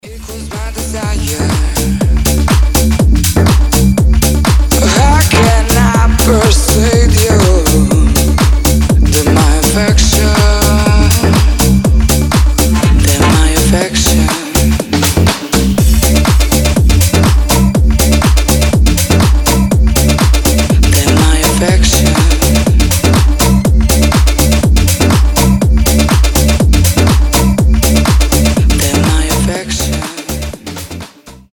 зажигательные
Club House
Tech House